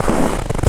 STEPS Snow, Walk 19-dithered.wav